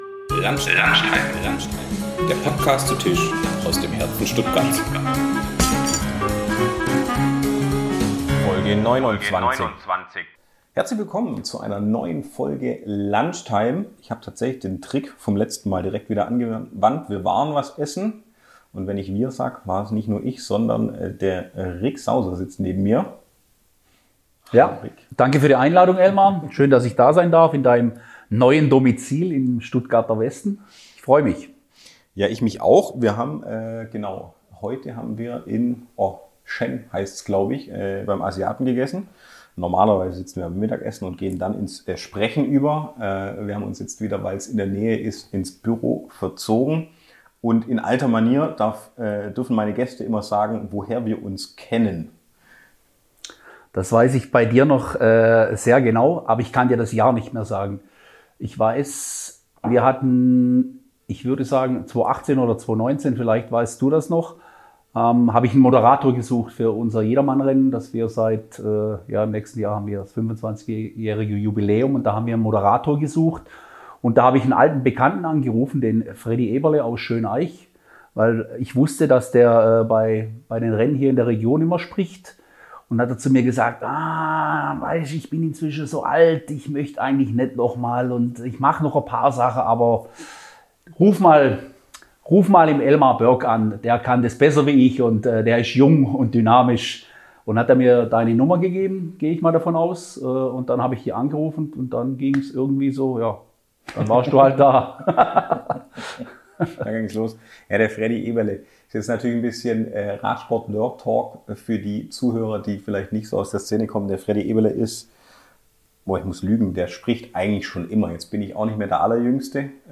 Erst Lunch bei SEN in der Schwabstraße, dann Aufnahme in der Sitzecke im Office.